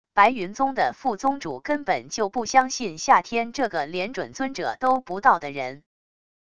白云宗的副宗主根本就不相信夏天这个连准尊者都不到的人wav音频生成系统WAV Audio Player